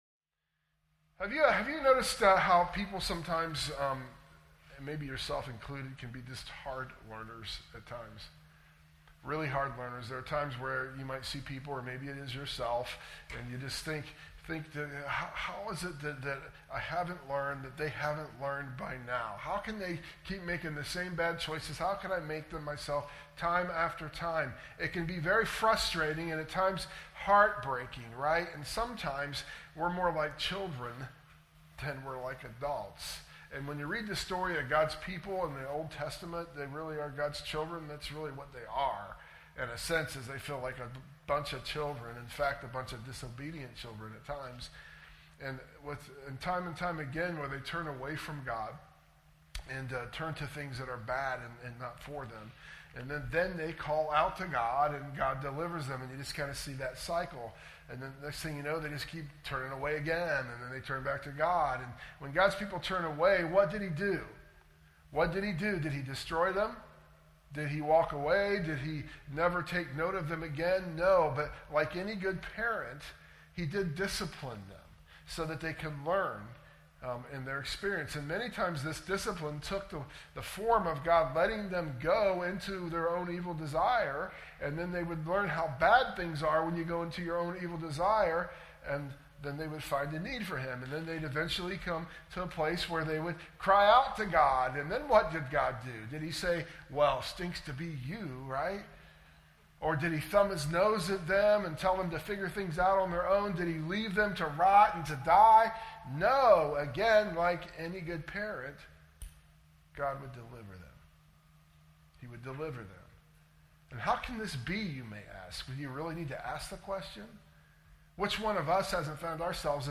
sermon_audio_mixdown_7_20_25.mp3